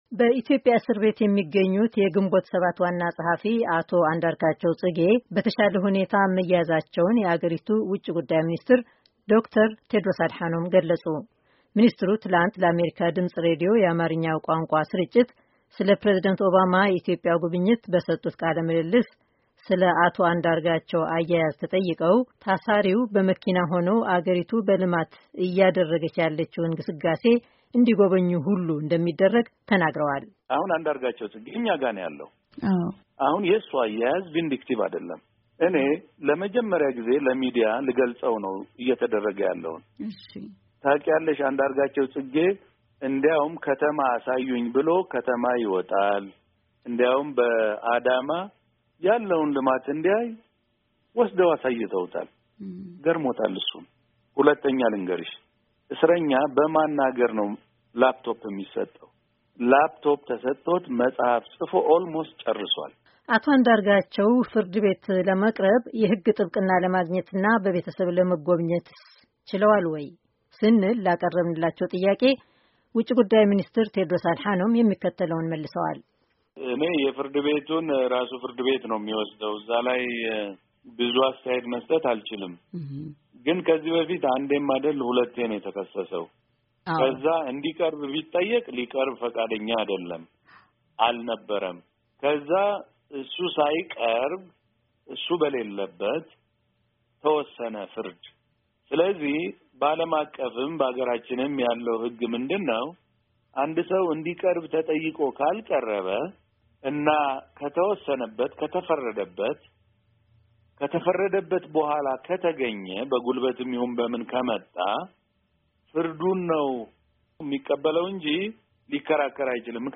በኢትዮጵያ እስር ቤት የሚገኙት የግንቦት ሰባት ዋና ጸሐፊ አቶ አንዳርጋቸዉ ጽጌ በተሻለ ሁኔታ መያዛቸዉን የአገሪቱ ዉጭ ግዳይ ሚኒስትር ዶክተር ቴዎድሮስ አድሃኖም ገለጹ። ሚኒስትሩ ትላንት ለአሜሪካ ድምጽ ራዲዩ የአማርኛዉ ቋንቋ ስርጭት ስለ ፕሬዚደንት ኦባማ ኢትዮጵያ ጉብኚት በሰጡት ቃለ ምልልስ ስለ አቶ አንዳርጋቸዉ አያያዝ ተጠይቀዉ ፣ ታሳሪዉ በመኪና ሆነዉ አገሪቱ በልማት እያደረገች ያለችዉን ግስጋሴ እንዲጎበኙ ሁሉ እንደሚደረግ ተናግረዋል።